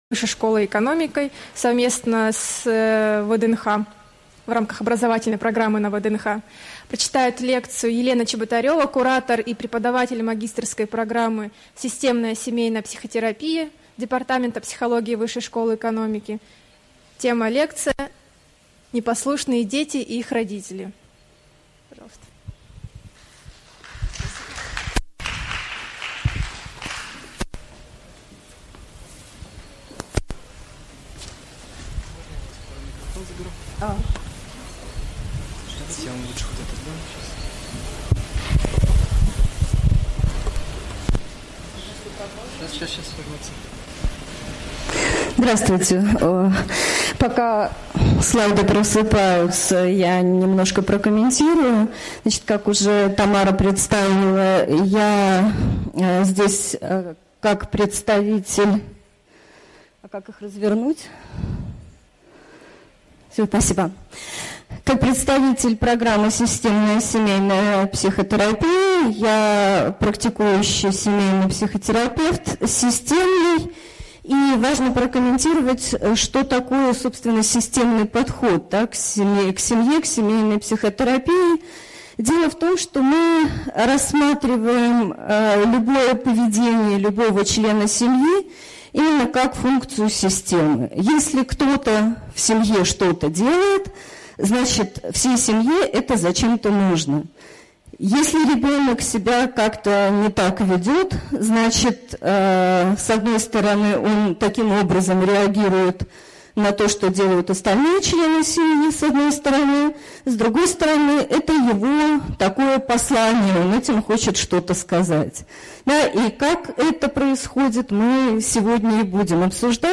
Аудиокнига Непослушные дети и их родители: почему дети не слушаются, и как им помочь слушаться?